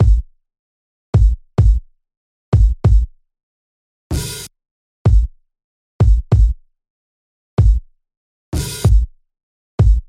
底架大鼓
Tag: 95 bpm Electronic Loops Drum Loops 1.70 MB wav Key : Unknown